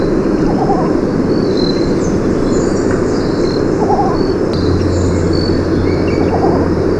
Avetoro Menor
Least Bittern
Ixobrychus exilis
VOZ: Una serie de notas graves y rápidas que recuerdan una tórtola.